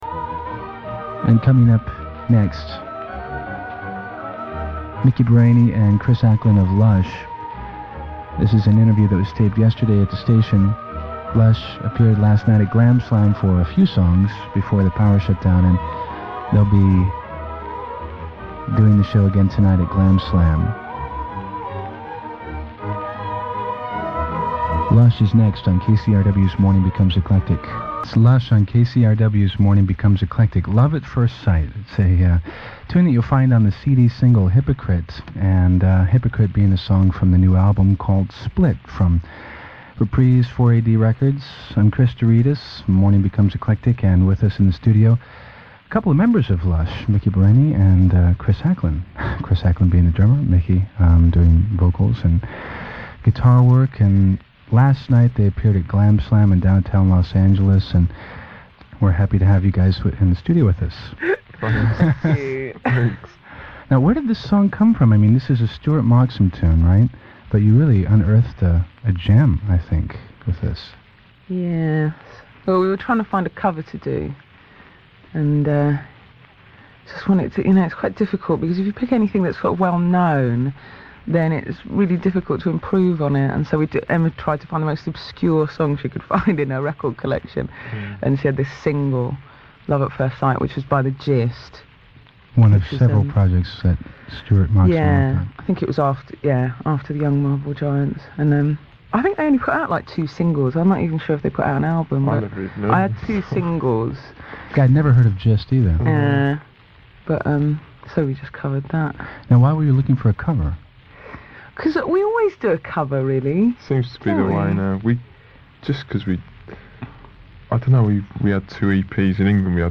Fantastic interview with Miki and Chris by Chris Douridas. Miki talks about making Split, where she got the name for Light From a Dead Star, the Gist cover Love at First Sight, dating Chris, coming up with Undertow, lots more.